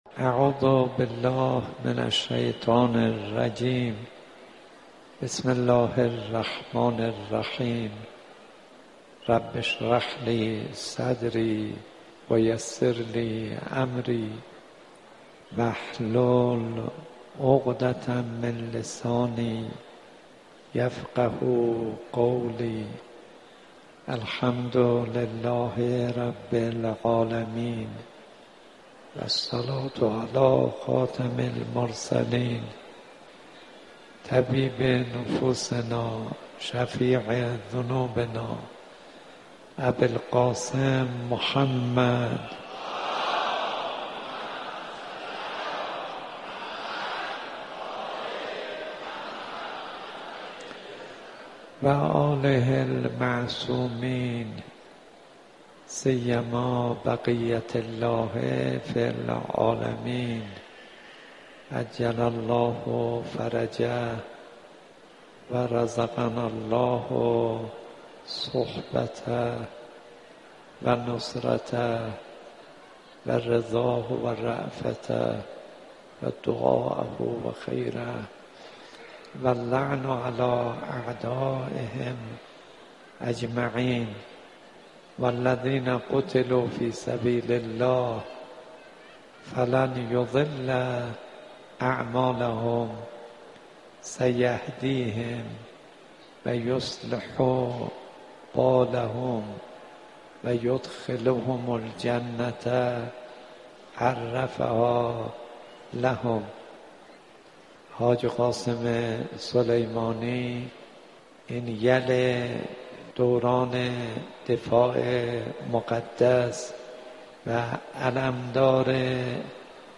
سخنرانی حجت الاسلام والمسلمین کاظم صدیقی با موضوع ویژگی حاج قاسم مرید واقعی حضرت زهرا (س)